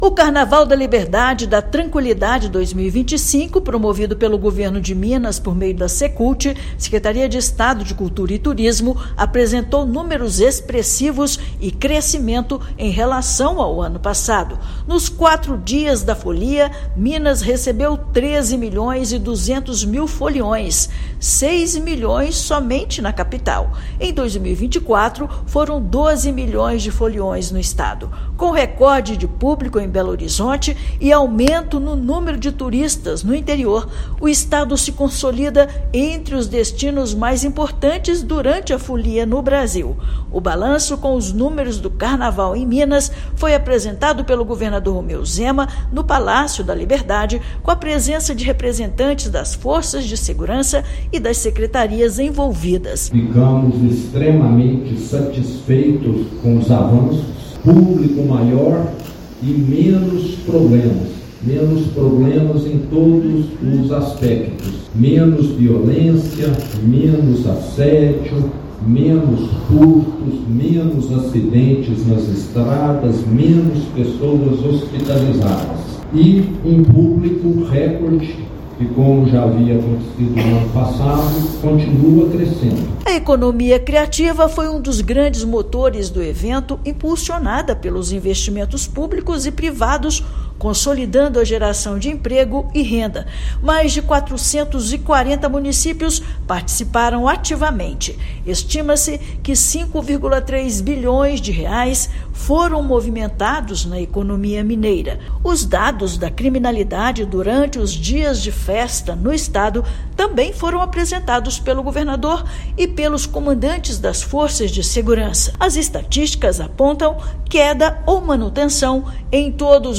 [RÁDIO] Sucesso de público, Carnaval em Minas Gerais conquista turistas e movimenta a economia em 2025
Oferecendo programação diversa para todos os perfis, estado se consolida com um dos principais destinos nacionais; levantamento aponta que 13,2 milhões de foliões circularam por Minas, sendo 6 milhões só em BH. Ouça matéria de rádio.